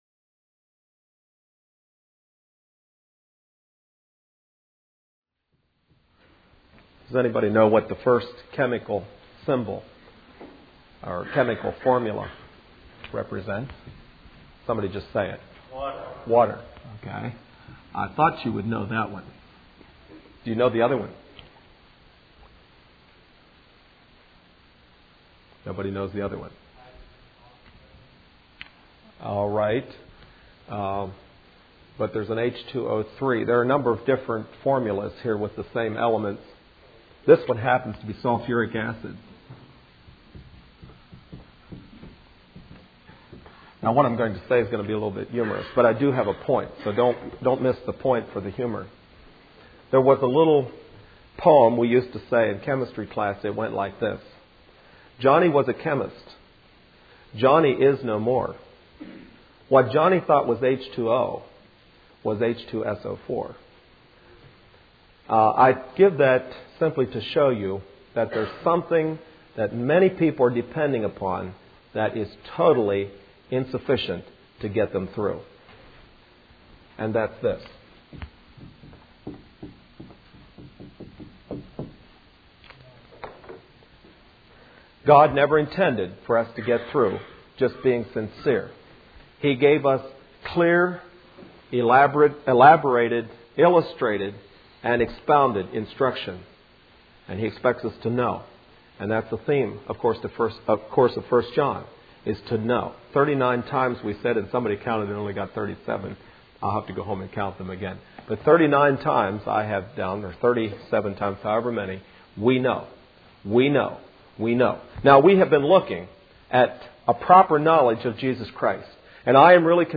Facing The Facts Service Type: Midweek Meeting Speaker